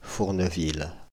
Fourneville (French pronunciation: [fuʁnəvil]
Fr-Fourneville.ogg.mp3